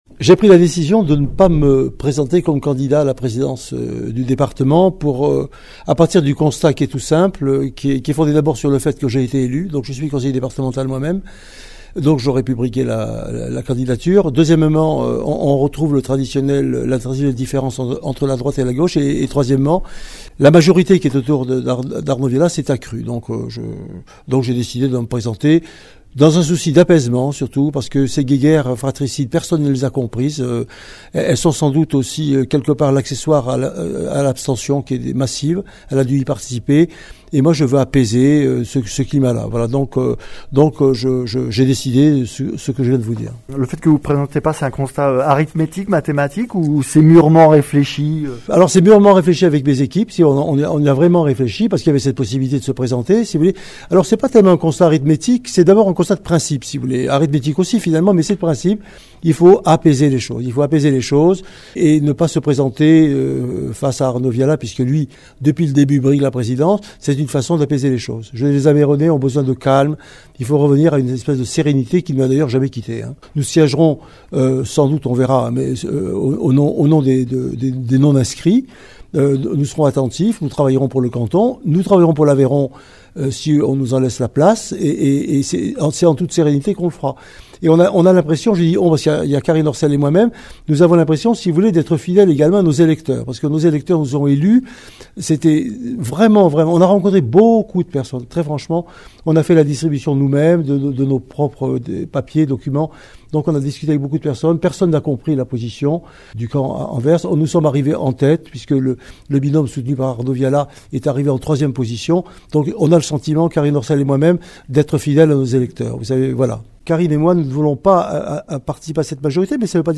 Interviews
Invité(s) : Jean François Galliard, Président sortant du conseil départemental de l’Aveyron